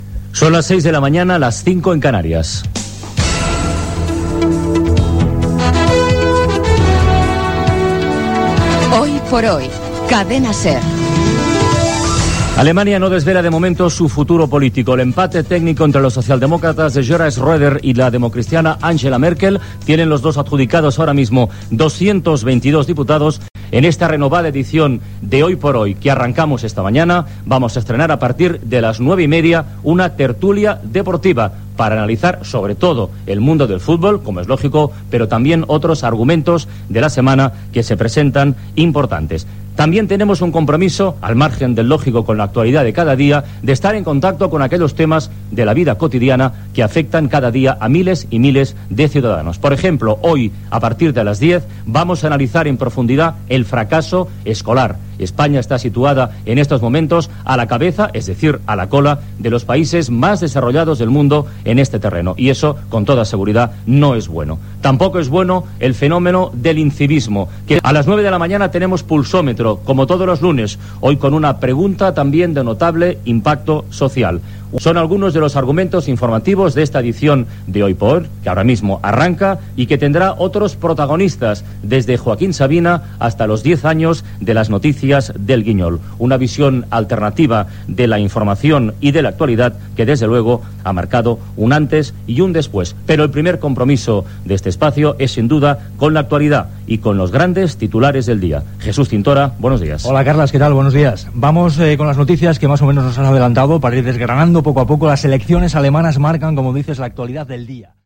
Hora, indicatiu del programa, data, eleccions alemanyes, presentació de l'edició renovada del programa, sumari de continguts.
Informatiu
Primera edició d'"Hoy por hoy" presentada per Carles Francino.